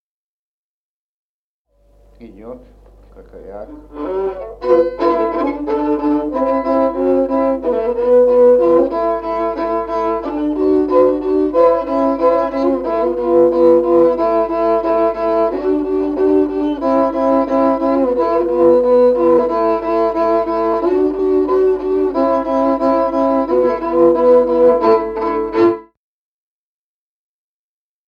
Музыкальный фольклор села Мишковка «Краковяк», партия 2-й скрипки.